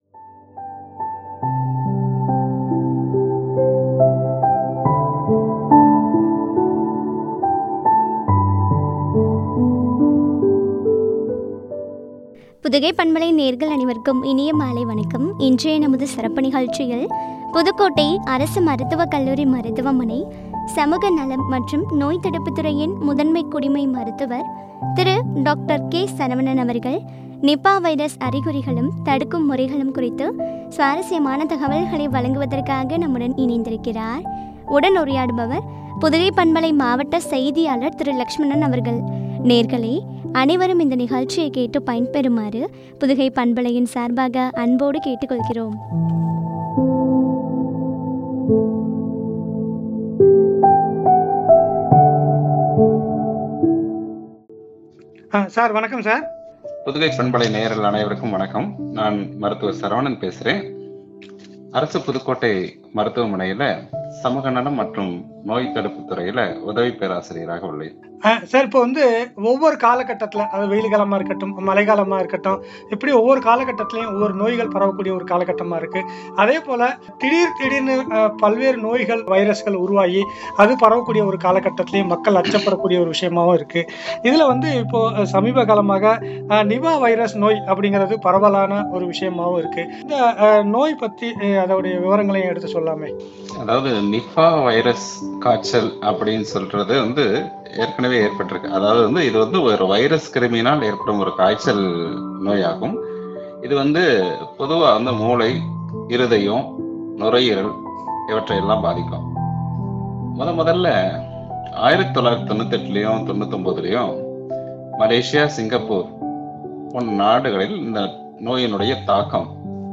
“நிபா வைரஸ்”- அறிகுறிகளும், தடுப்பு முறைகளும் உரையாடல்.